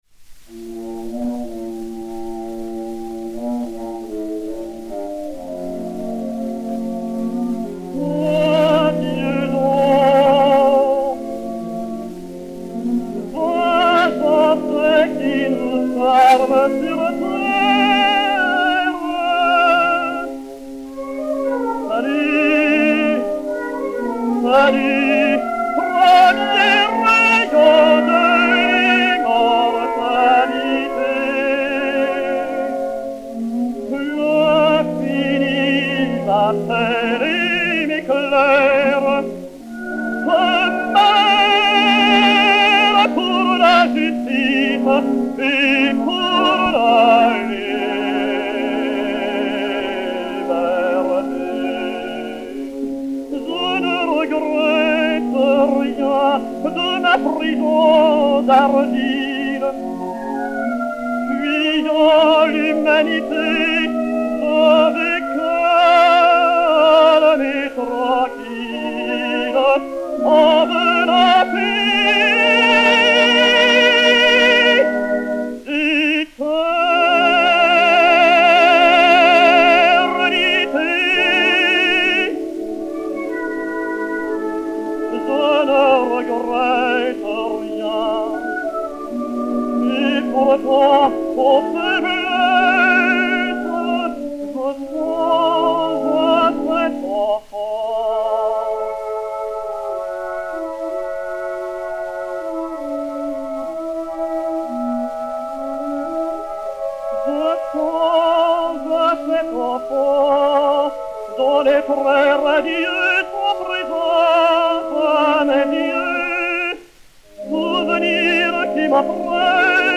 et Orchestre
XP 5061, enr. à Paris en 1910